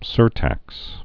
(sûrtăks)